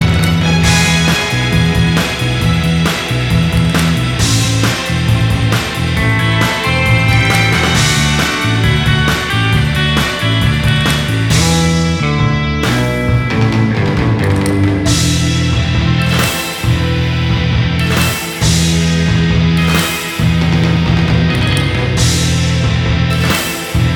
Minus All Guitars Indie / Alternative 3:39 Buy £1.50